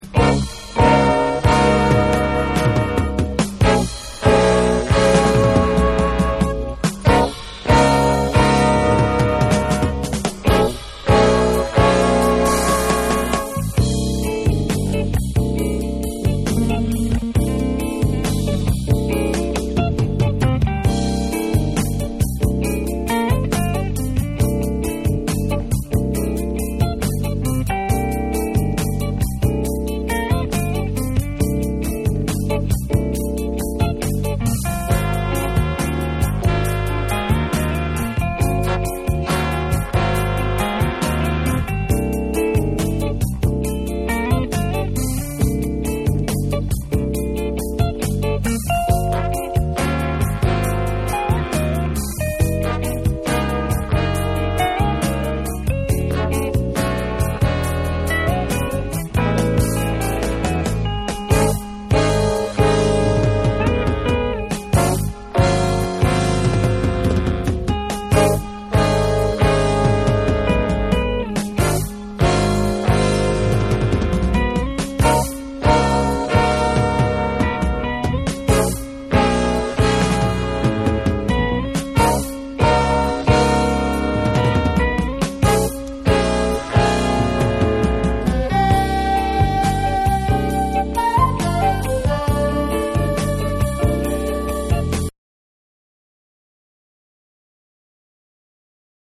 UKのファンク／ディスコ・バンド
DANCE CLASSICS / DISCO